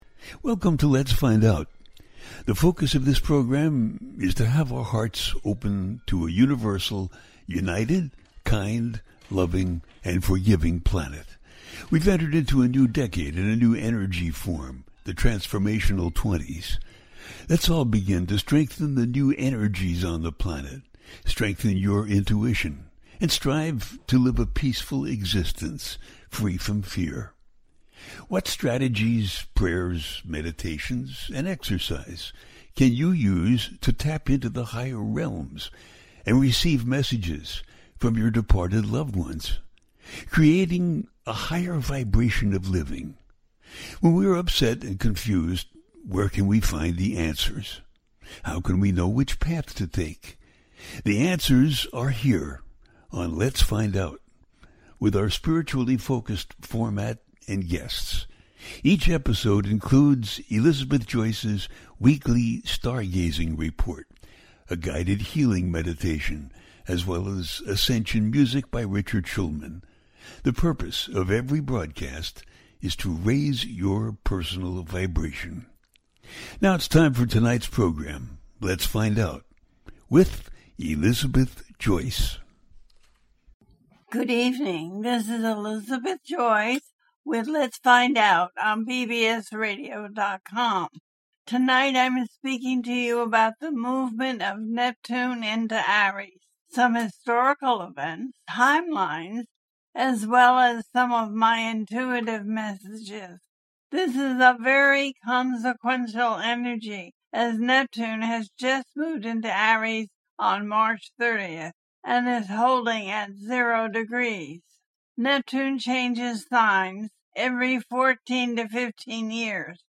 Neptune In Aries 2025 through to 2039 - A teaching show